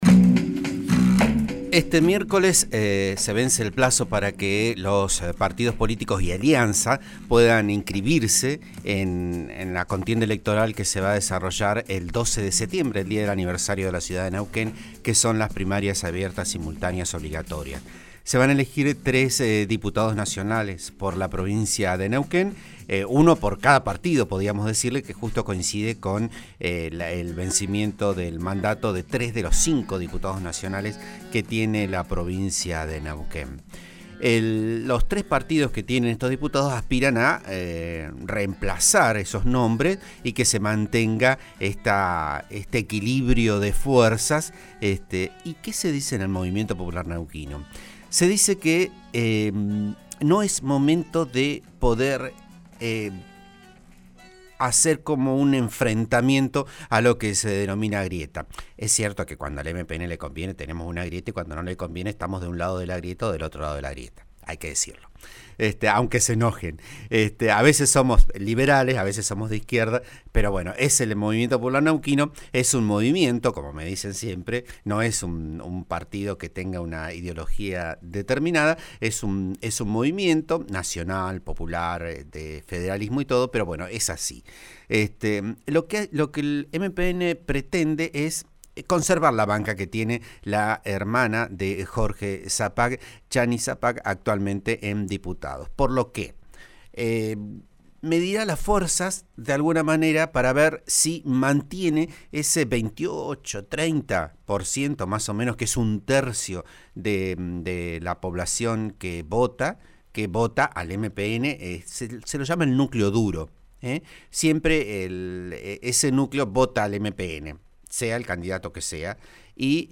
Editorial: ¿Qué pueden esperar los partidos políticos de Neuquén en estas elecciones?